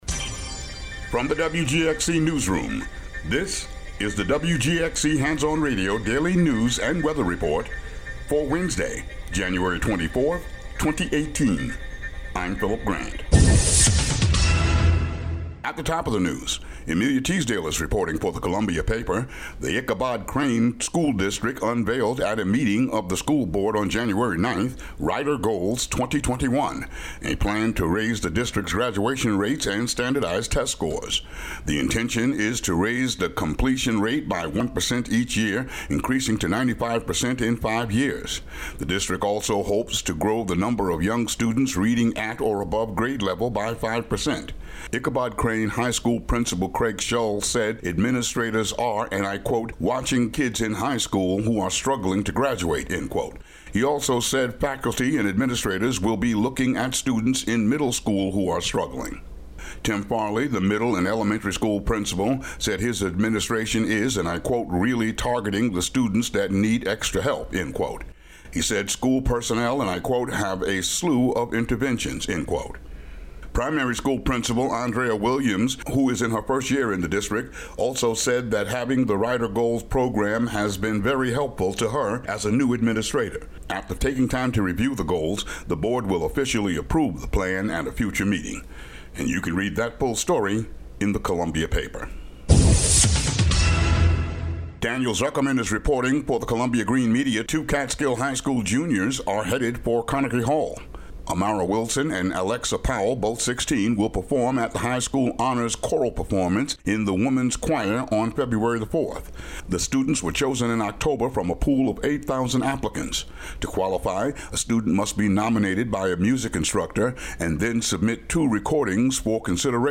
WGXC Local News